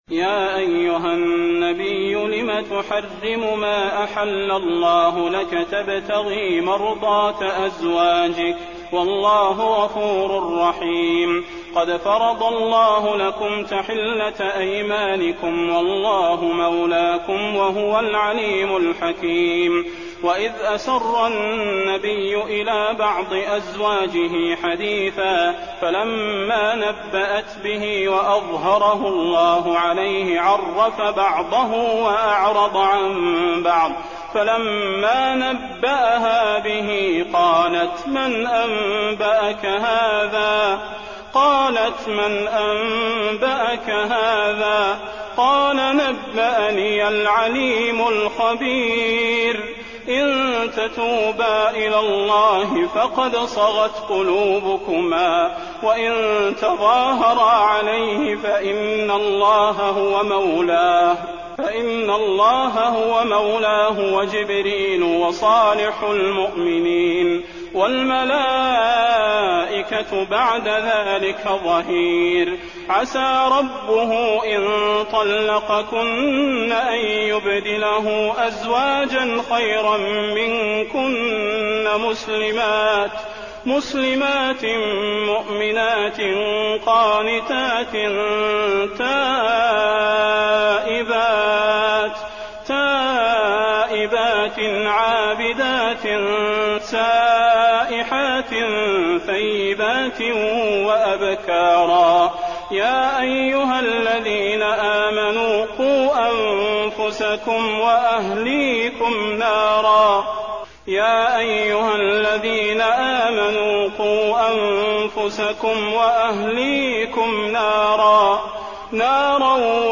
المكان: المسجد النبوي التحريم